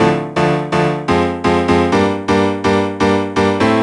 cch_synth_loop_eighty_125_Am.wav